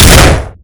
flashbang.ogg